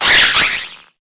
Cri_0877_EB.ogg